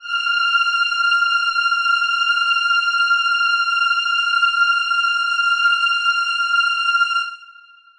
Choir Piano (Wav)
F6.wav